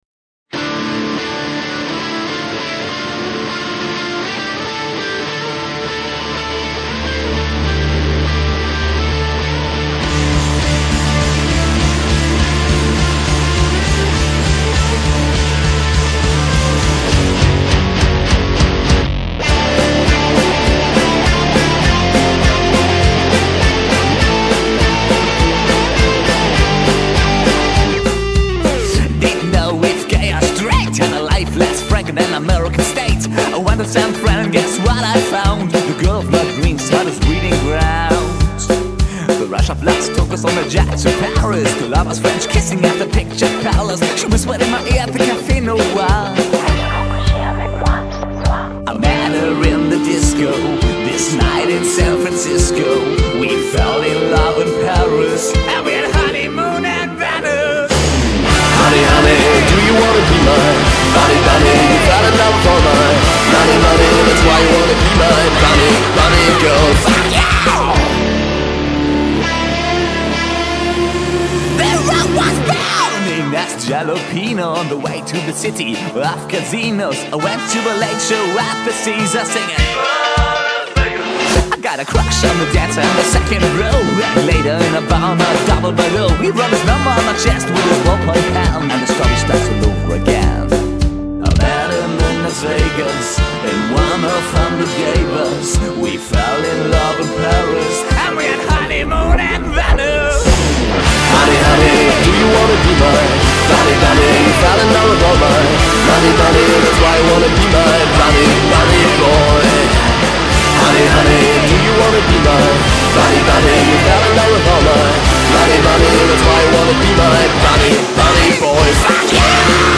glamrock band
Vocals, Guitars
Drums
Demo Songs